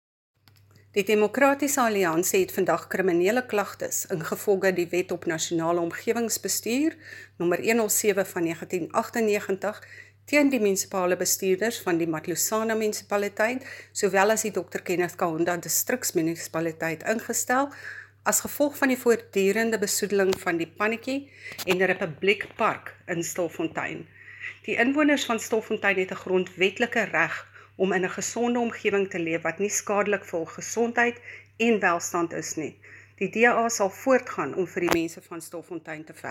Note to Editors: Please find the attached soundbite in  English and
Afrikaans by Cllr Bea Campbell-Cloete